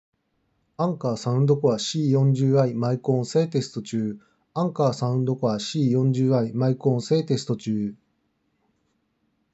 解析度は少し抑え気味だが、そのままの声を届けてくれるマイク性能って印象です。
✅「Anker Soundcore C40i」のマイクテスト
声をそのまま届けてくれるって意味では「Anker Soundcore C40i」の方が近い。